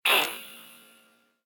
block-destroy.ogg